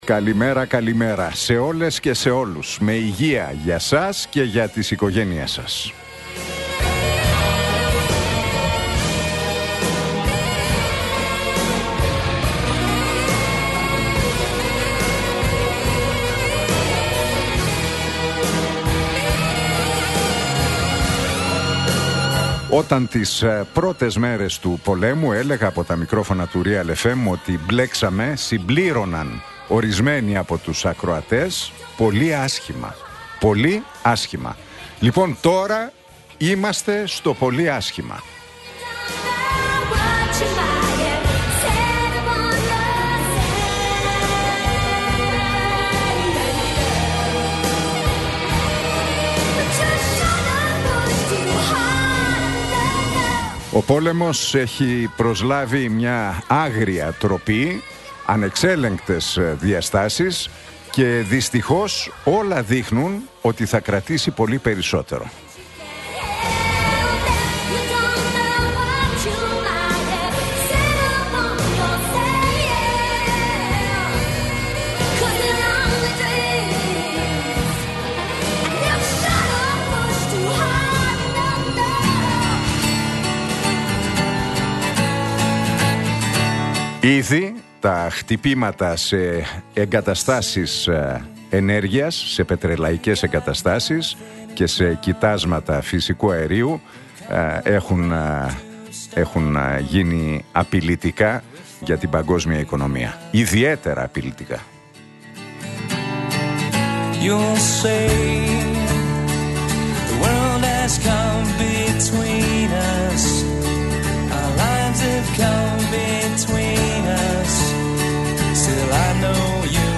Ακούστε το σχόλιο του Νίκου Χατζηνικολάου στον ραδιοφωνικό σταθμό Realfm 97,8, την Πέμπτη 19 Μαρτίου 2026.